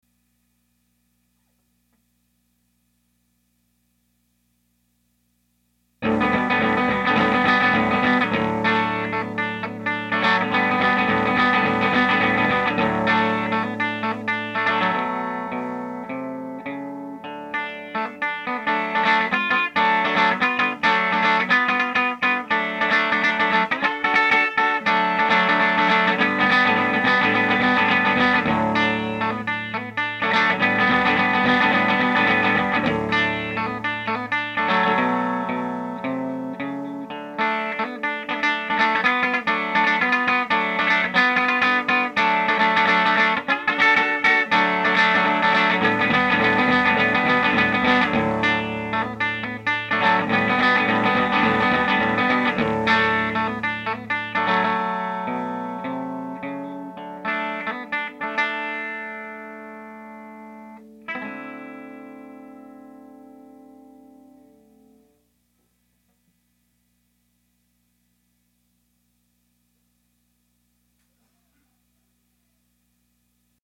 Everything is set the same for both of them, totally dry, no reverb, no post processing. Don’t mind my multiple mistakes, the rattle is the snare drum that I didn’t disengage the snare from, sorry.
Only one pickup, so only one sound.